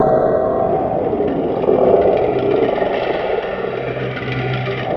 CAVES C2.wav